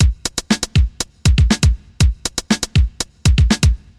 电子节拍
描述：在Redrum中用Reason 9和我自己的鼓声样本实现的。
Tag: 120 bpm Electronic Loops Drum Loops 689.18 KB wav Key : Unknown